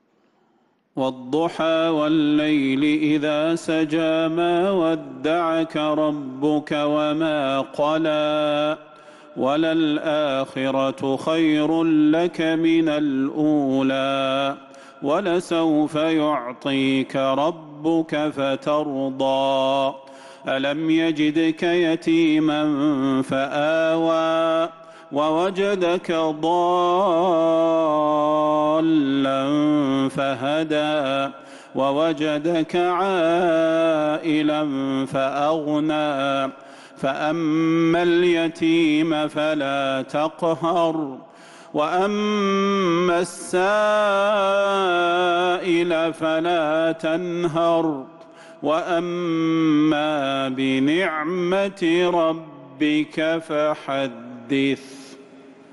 سورة الضحى | ربيع الآخر 1447هـ > السور المكتملة للشيخ صلاح البدير من الحرم النبوي 🕌 > السور المكتملة 🕌 > المزيد - تلاوات الحرمين